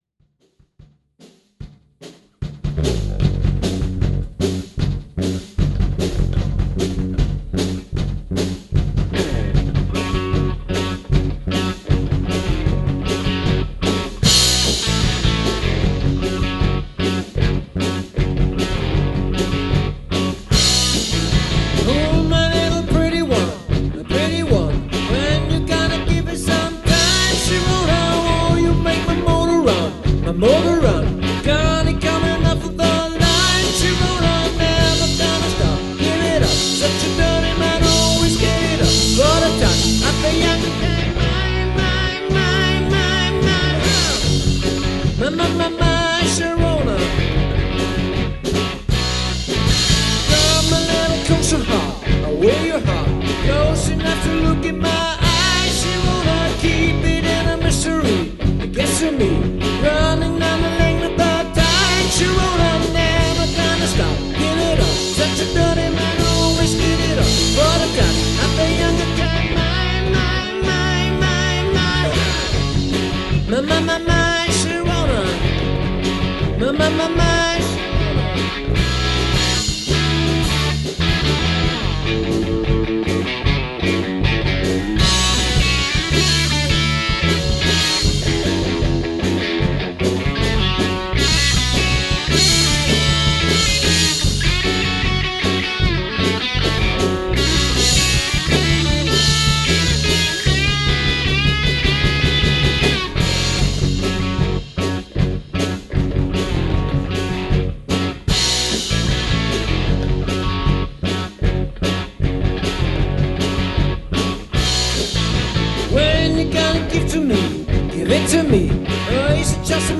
rock and hard rock
vocals, guitar
bass, backing vocals
drums, percussion
Mitschnitte aus dem Proberaum